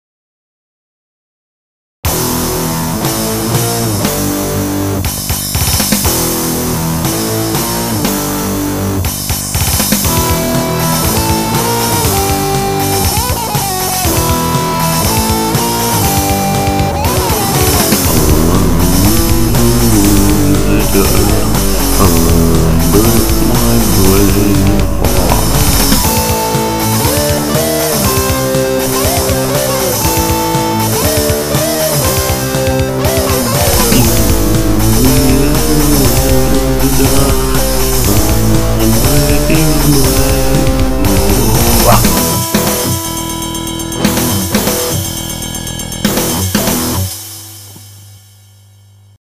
Дэмки на скорую руку это.